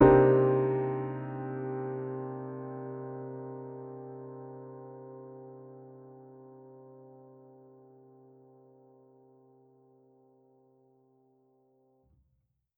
Index of /musicradar/jazz-keys-samples/Chord Hits/Acoustic Piano 1
JK_AcPiano1_Chord-Cm9.wav